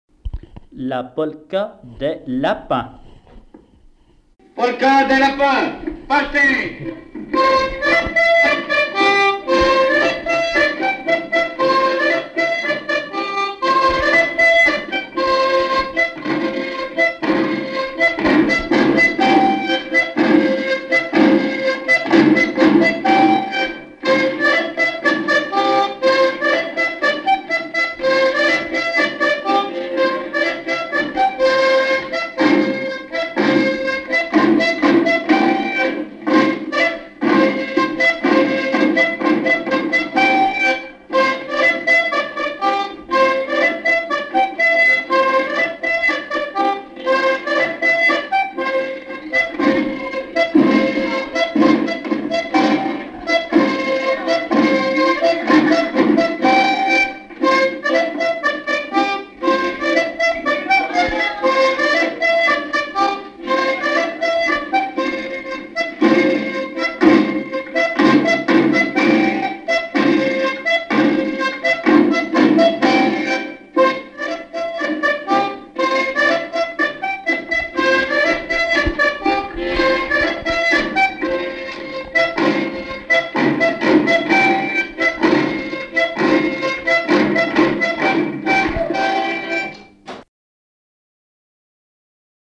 danse : polka lapin
Répertoire du violoneux
Pièce musicale inédite